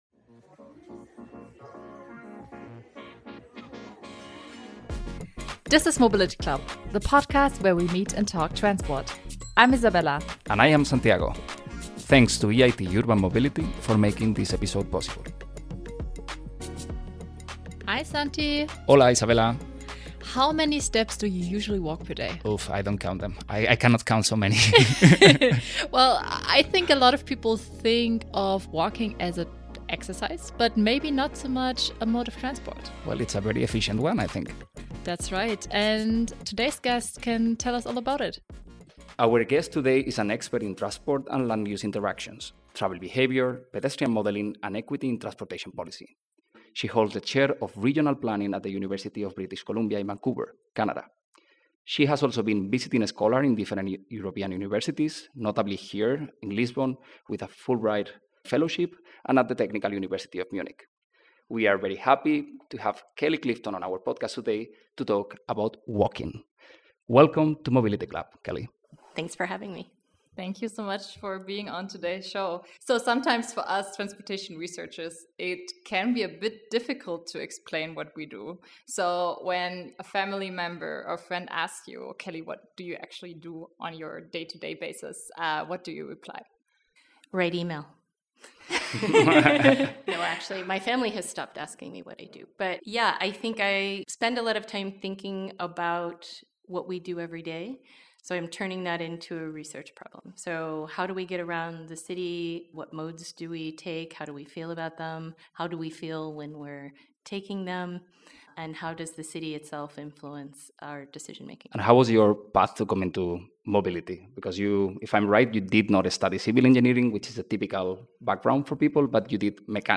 It's unabashedly academic and somewhat transport nerdy, feels a bit like a conference panel sometimes (just shorter and without rambling comments 😅) but I would say still quite accessible.